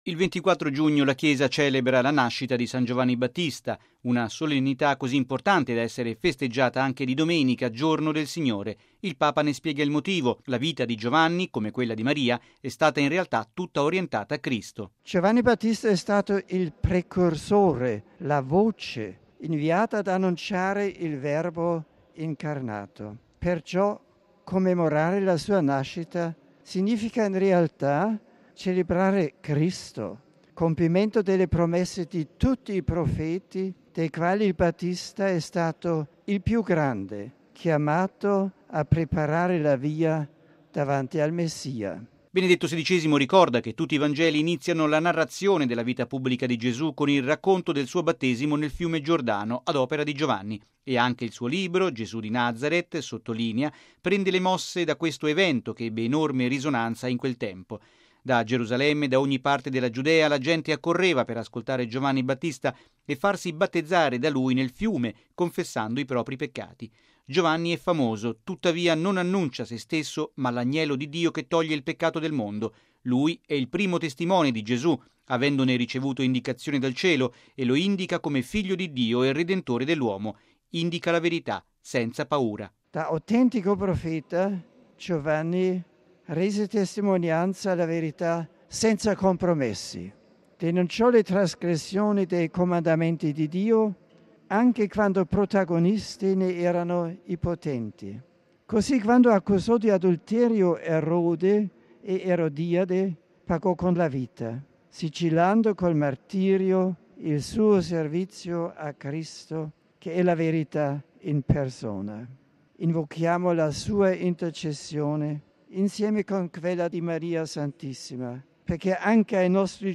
Oltre 50 mila i pellegrini presenti in Piazza San Pietro.